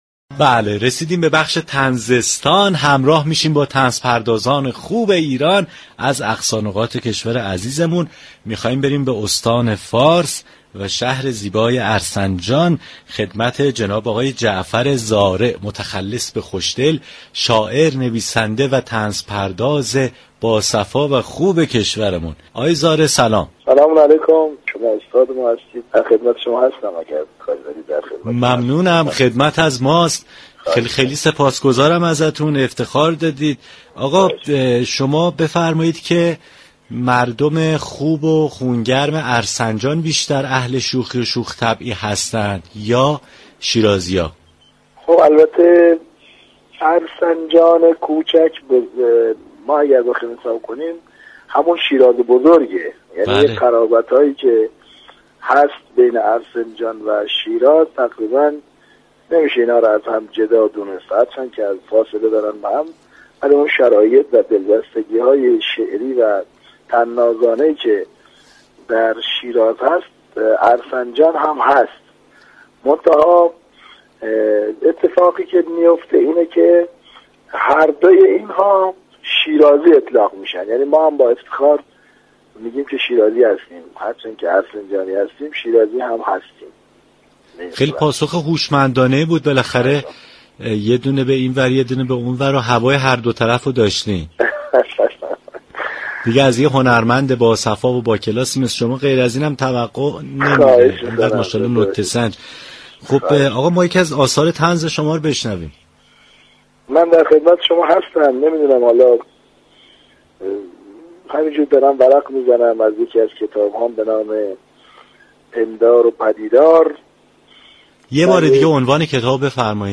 گفتگوی رادیو صبا